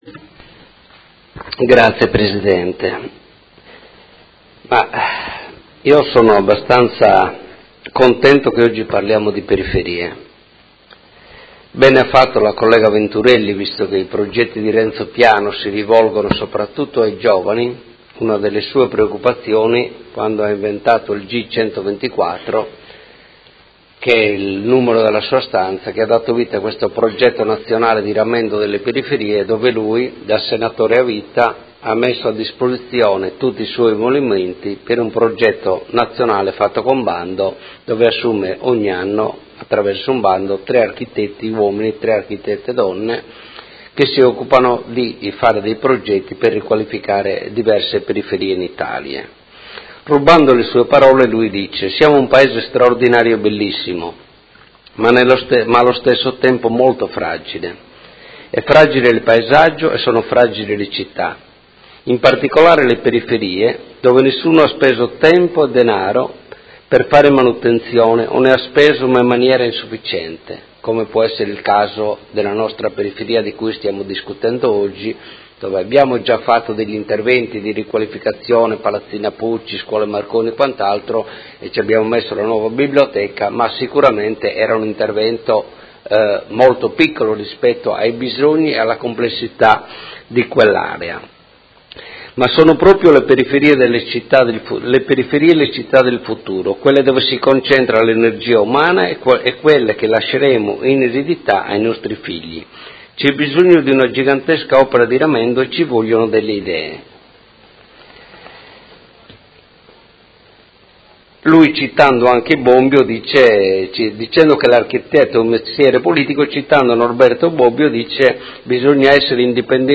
Seduta del 25/05/2017 Dibattito su Delibera Linee di indirizzo per il riordino funzionale e morfologico dell’ Area urbana a Nord di Modena “Fascia ferroviaria” – Approvazione Masterplan e su Ordine del giorno 80690 avente per oggetto: Progetto Periferie, rigenerazione e innovazione.